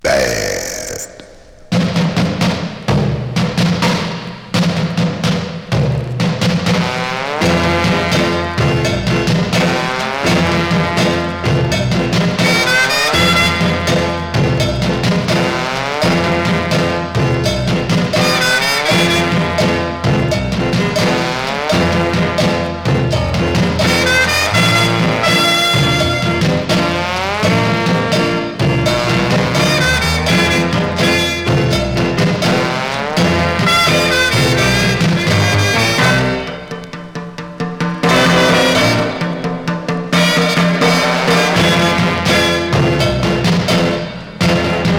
様々なゴリゴリ感が直撃、肉感溢れる音に満たされます。